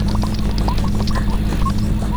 airplaneSqueaker.wav